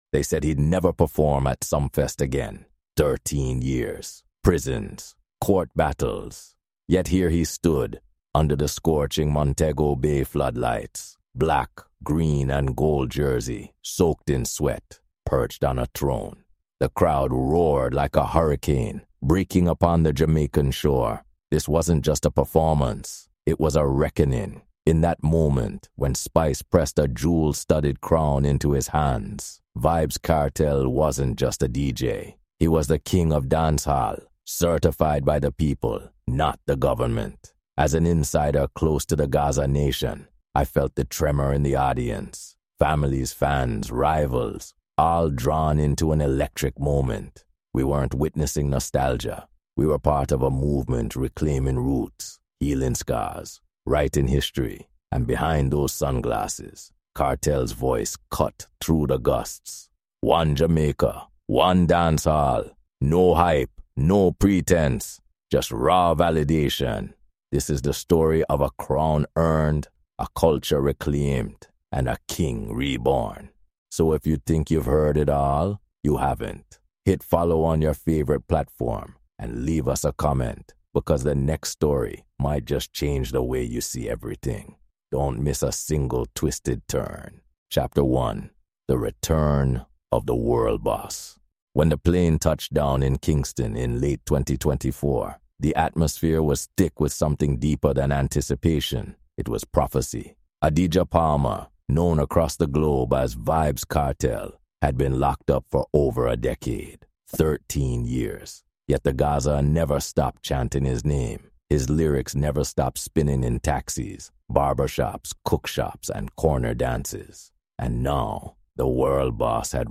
In this raw and reflective Caribbean music documentary, a dancehall journalist returns to Jamaica in 2025 to witness the long-awaited coronation of Vybz Kartel at Reggae Sumfest.